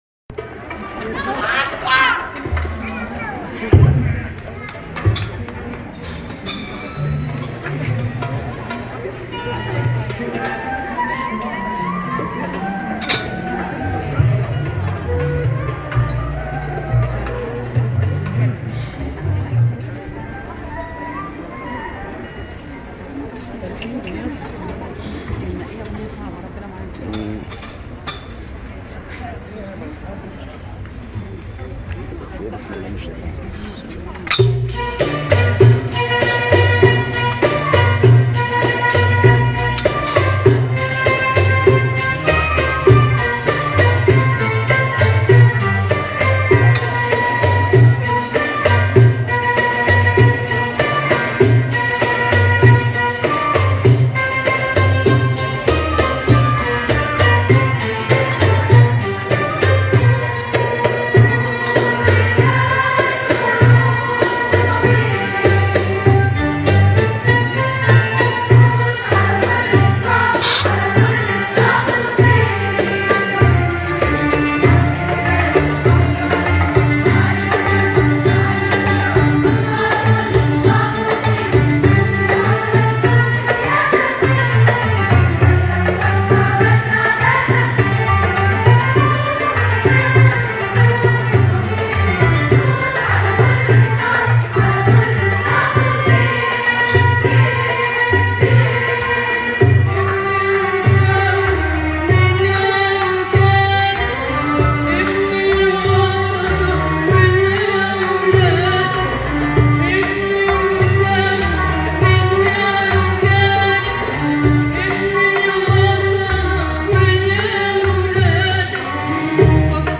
حفل الاوبرا